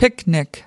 /kaɪt/